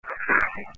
Saturday, June 16th 2007 - We investigated a family home in Franklin, Massachusetts
EVP's